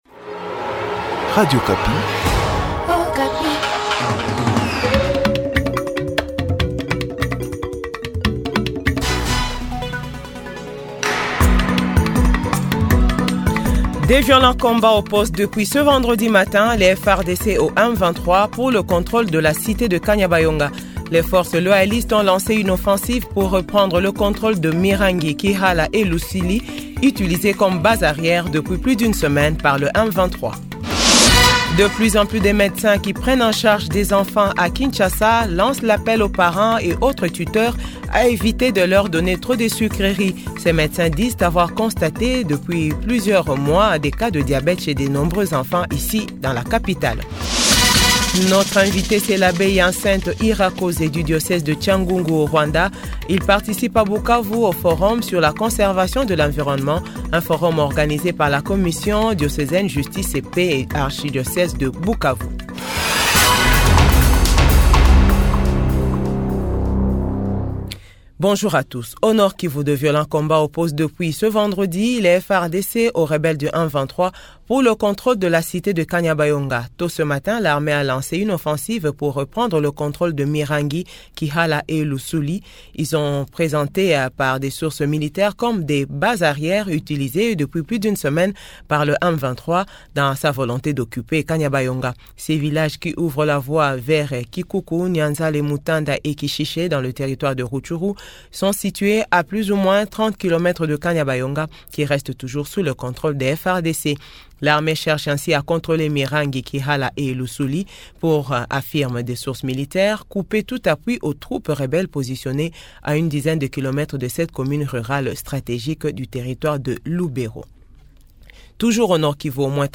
JOURNAL FRANÇAIS 12H00